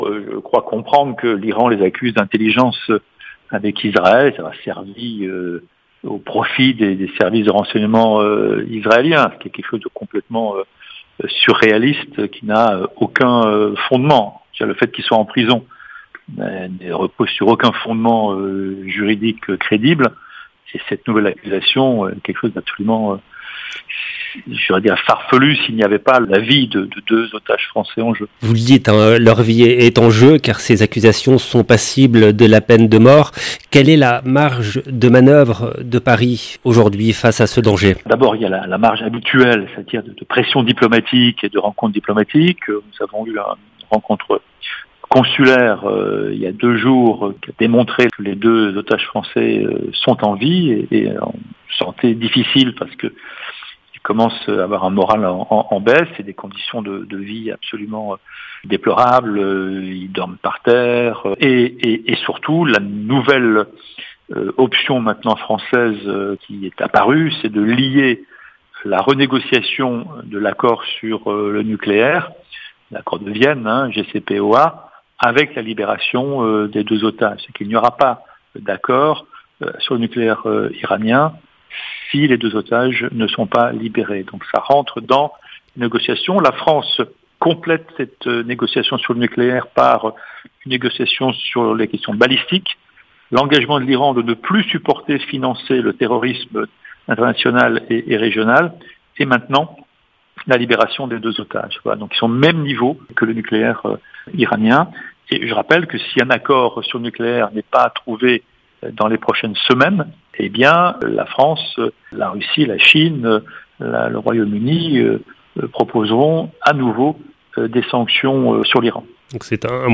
Interview avec Bruno Fuchs